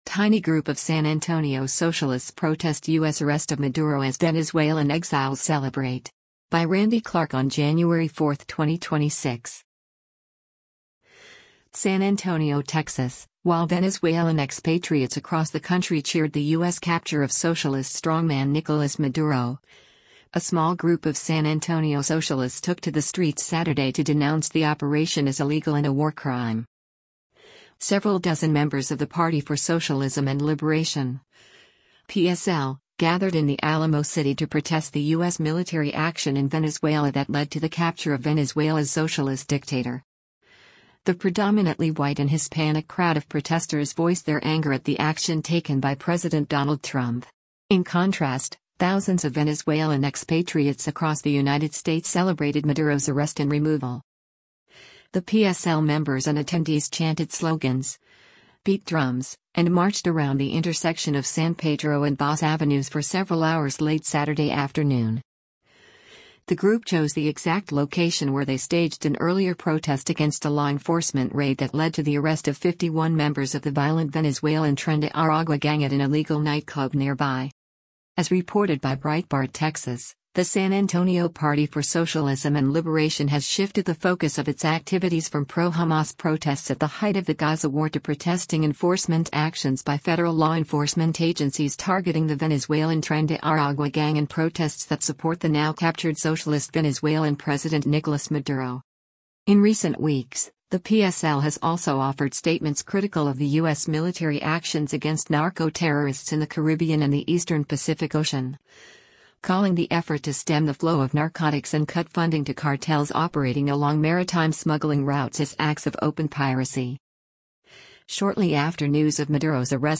The PSL members and attendees chanted slogans, beat drums, and marched around the intersection of San Pedro and Basse Avenues for several hours late Saturday afternoon.